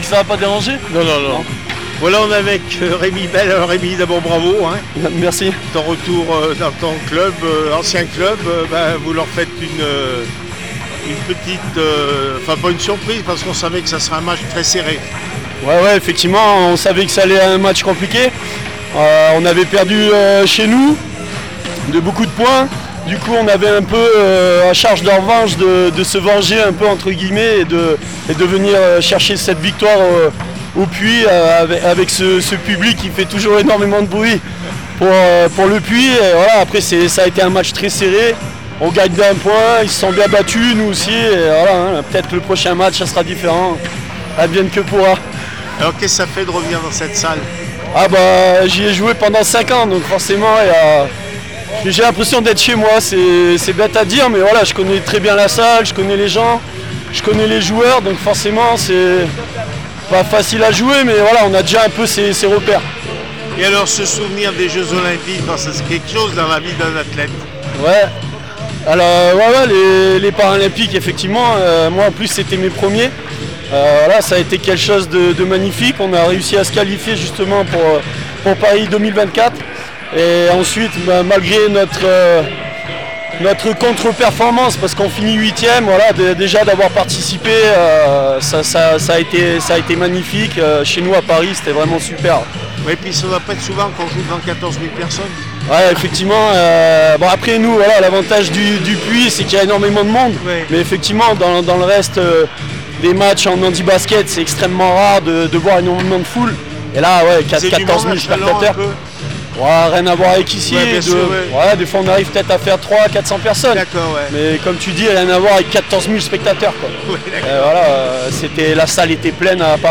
handi basket Elite les aigles du Velay 63-64 élan de chalon réaction après match